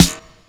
ELECSNR.wav